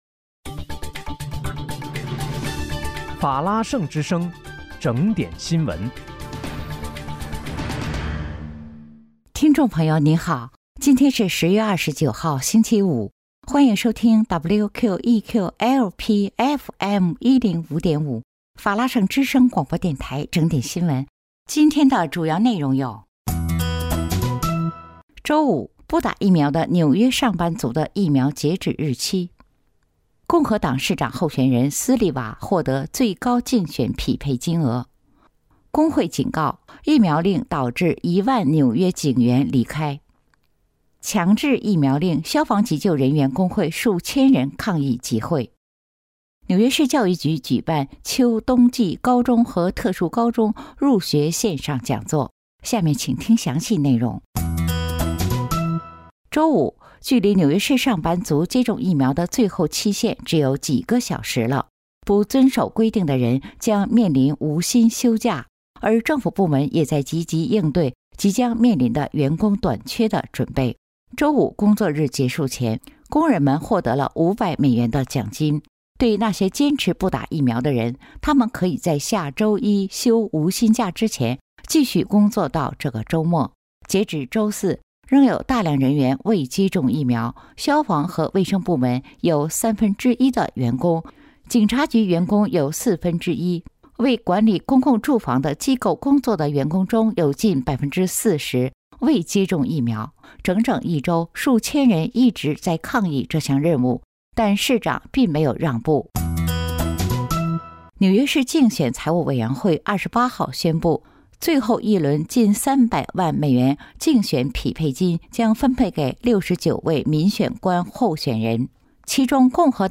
10月29日（星期五）纽约整点新闻